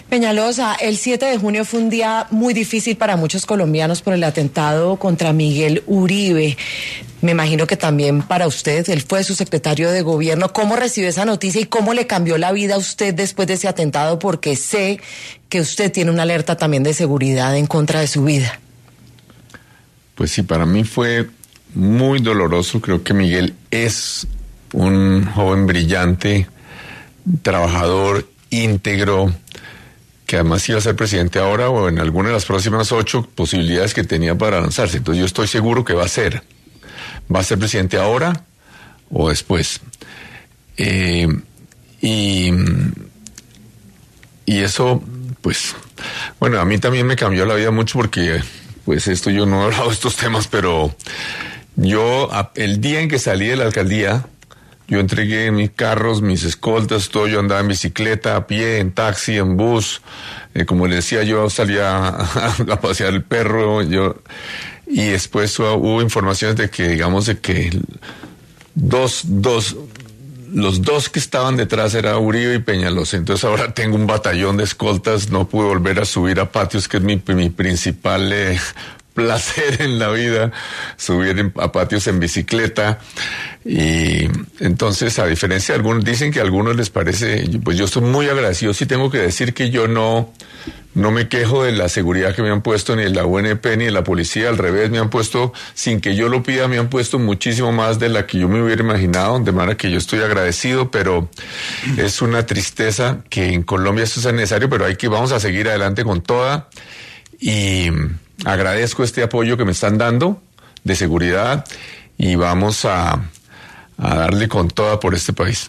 El exalcalde de Bogotá estuvo en ‘Sin Anestesia’ para hablar de las razones por las que le reforzaron su esquema de seguridad.
En ese orden de ideas, Peñalosa pasó por los micrófonos de ‘La Luciérnaga’ para abordar ‘Sin Anestesia’ temas asociados al atentado de Miguel Uribe y su situación de seguridad actual.